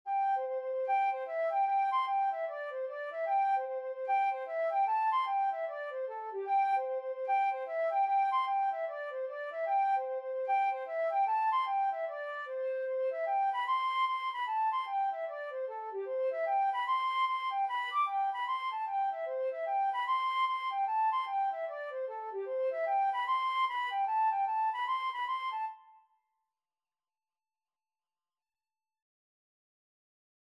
Flute version
C major (Sounding Pitch) (View more C major Music for Flute )
4/4 (View more 4/4 Music)
Flute  (View more Intermediate Flute Music)
Traditional (View more Traditional Flute Music)
Irish